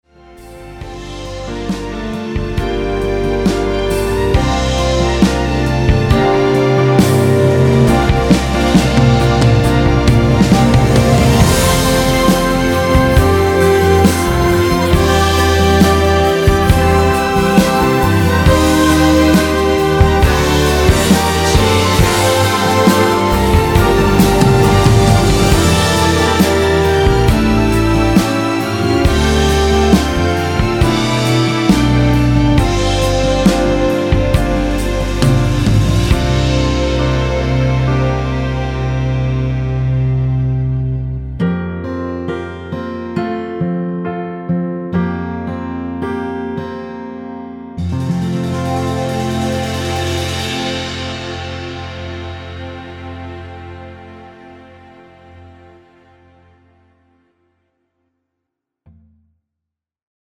이곡은 코러스가 마지막 한부분만 나와서 그부분만 제작이 되었습니다.(미리듣기 확인)
원키에서(+1)올린 코러스 포함된 MR입니다.
앞부분30초, 뒷부분30초씩 편집해서 올려 드리고 있습니다.
중간에 음이 끈어지고 다시 나오는 이유는